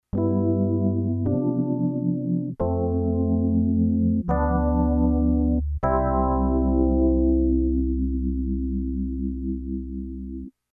PAN　：　パンと言えば、エレピにカマす昔懐かしいトレモロですな。MONOのモードにするのをお忘れなく。
panlfo.mp3